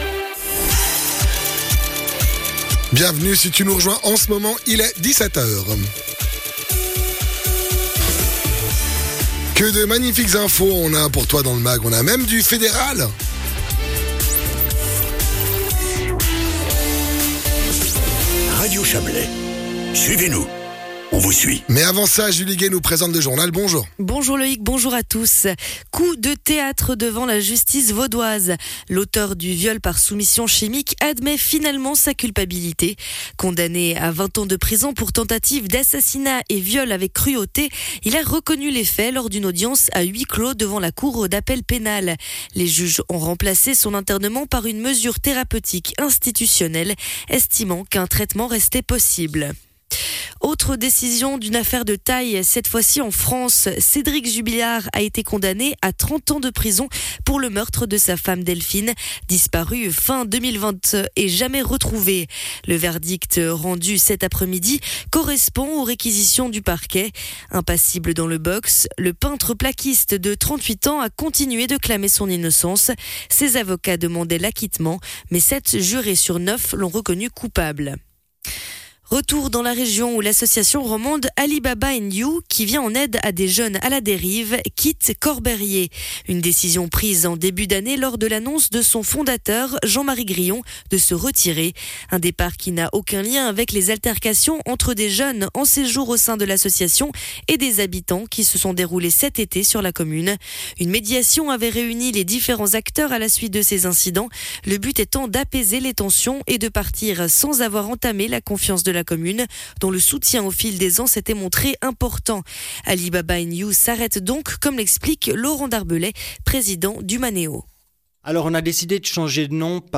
Les infos de 17h00 du 17.10.2025